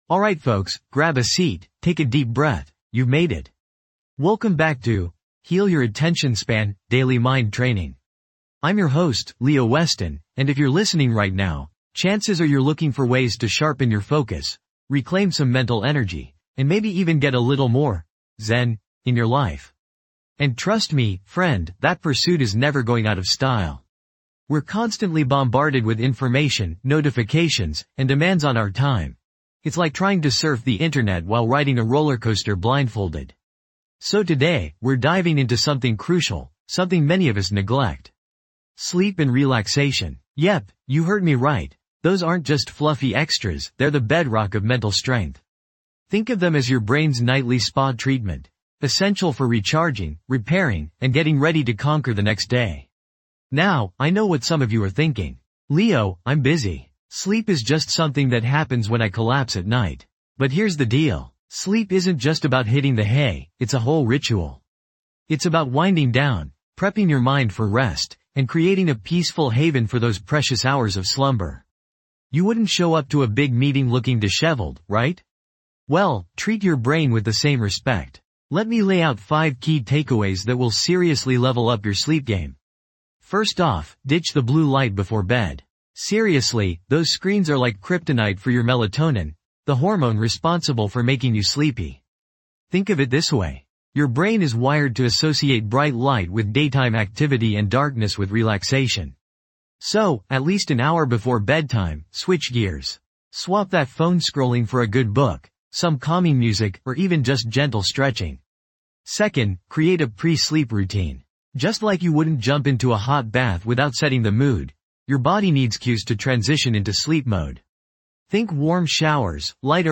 Key Takeaways:. Practical tips for improving sleep, relaxation techniques, stress reduction methods, mindfulness practices, and guided meditation exercises.
This podcast is created with the help of advanced AI to deliver thoughtful affirmations and positive messages just for you.